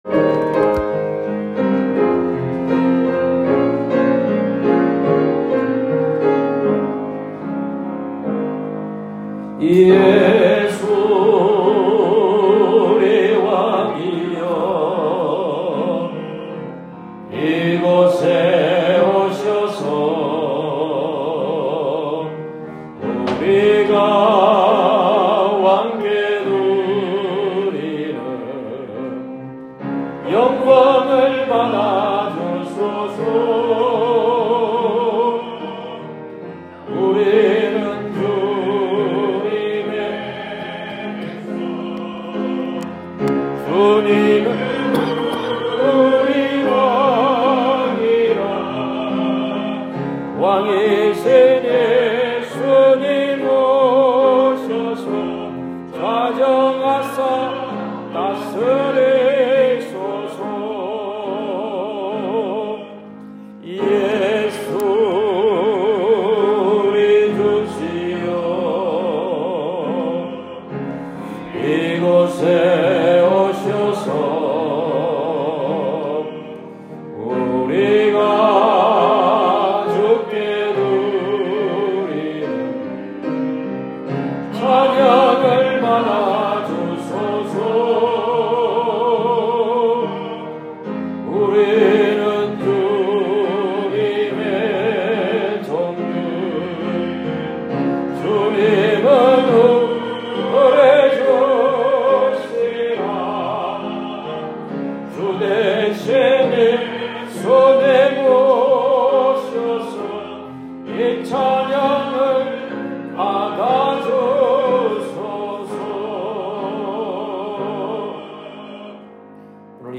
2023년 10월 15일 주일예배